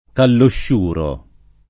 [ kallošš 2 ro ]